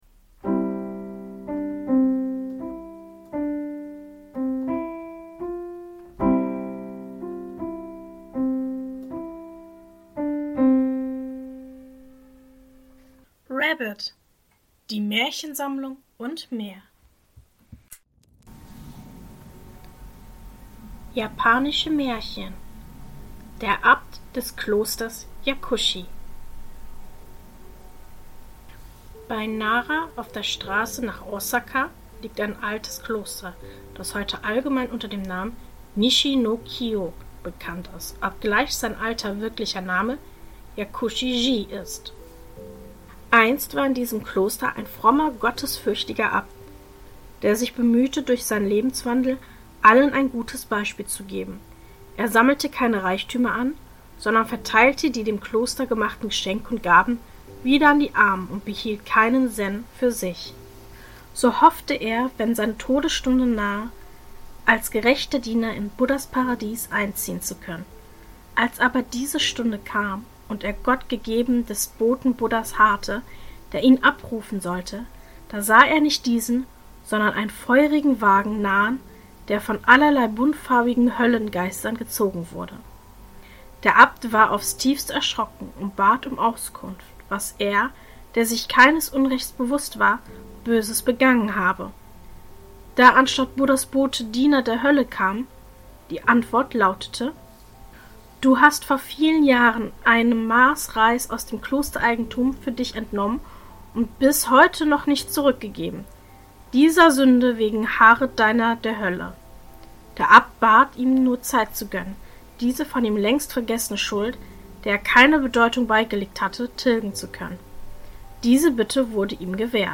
In der heutigen Folge lese ich Folgendes vor: 1. Der Abt des Klosters Yakushi. 2.